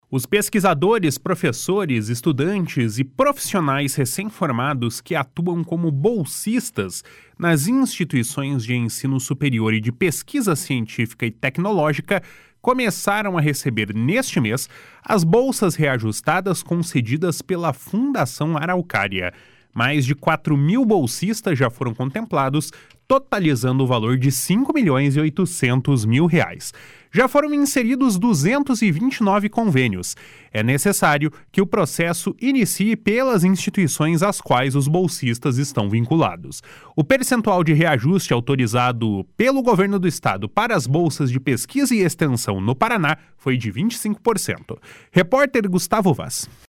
O percentual de reajuste autorizado pelo Governo do Estado para as bolsas de pesquisa e extensão no Paraná foi de 25%. (Repórter